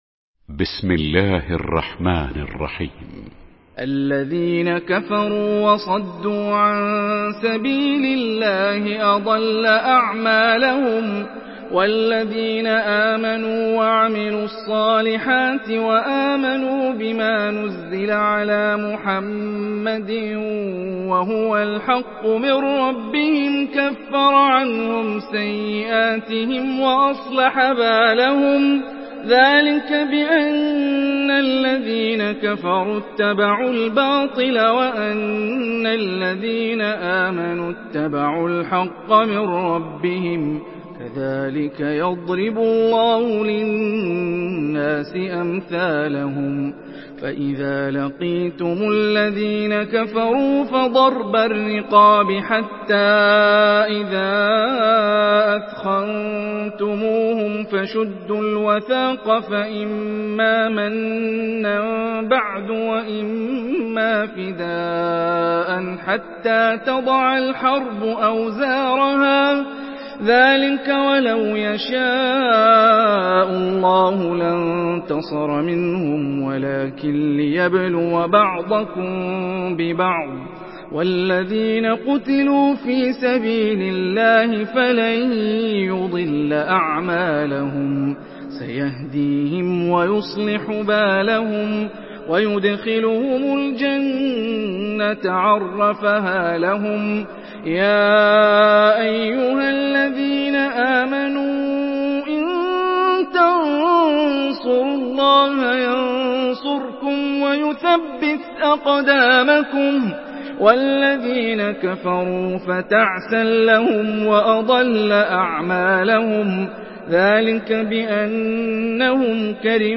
Surah Muhammad MP3 in the Voice of Hani Rifai in Hafs Narration
Surah Muhammad MP3 by Hani Rifai in Hafs An Asim narration.
Murattal